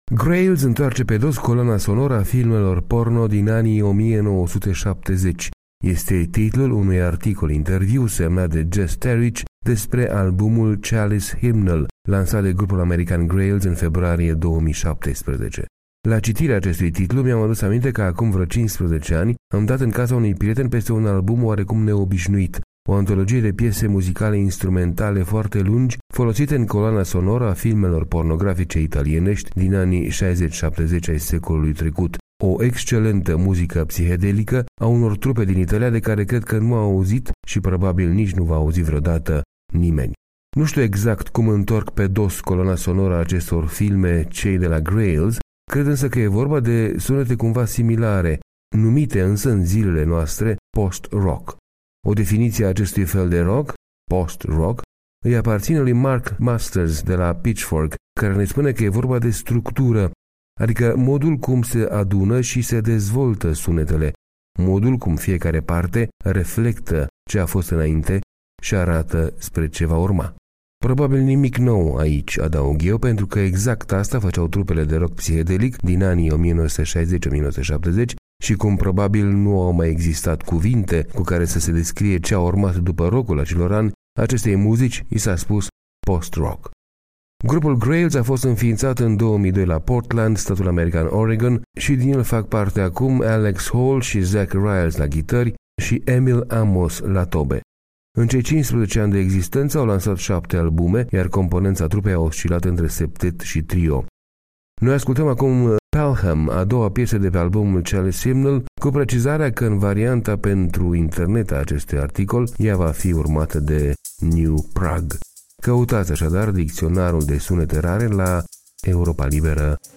O arhitectură sonică, deci.
la ghitări